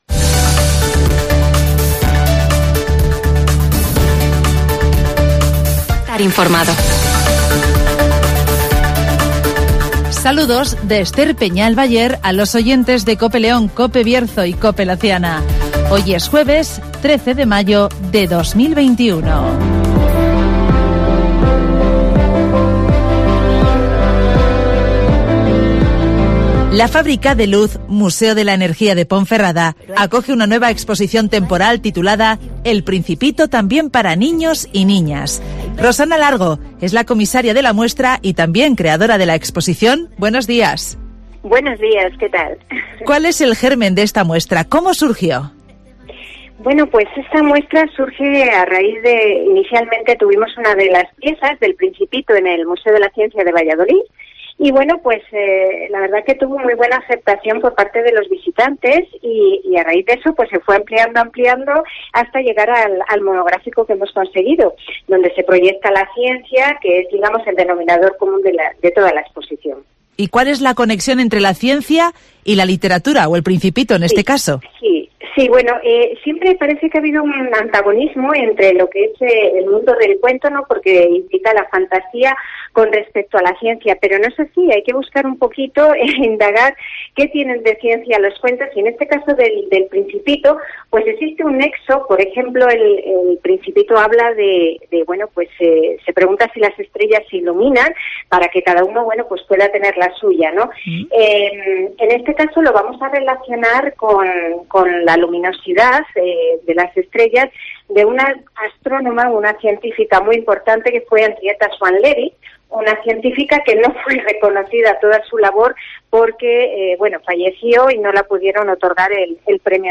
El Museo de la Energía de Ponferrada acoge la nueva exposición 'El Principito también para niños y niñas' (Entrevista